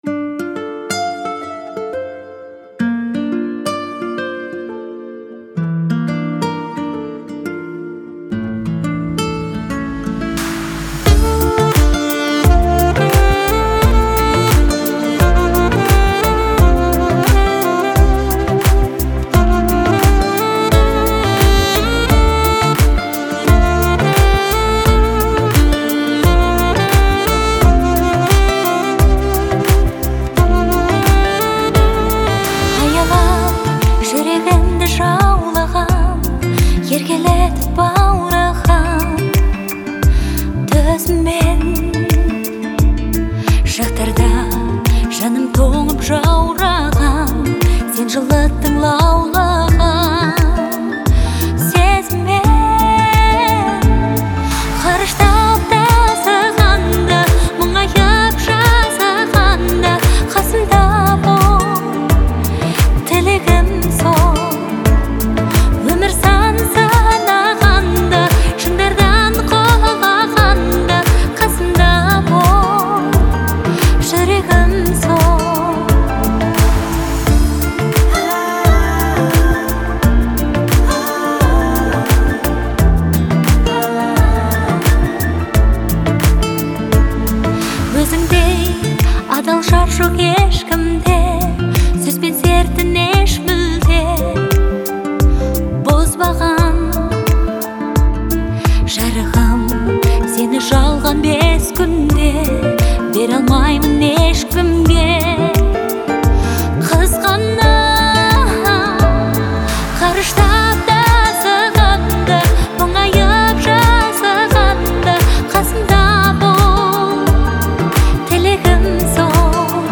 выполненная в жанре поп.
мелодичным сопровождением, создающим атмосферу уюта.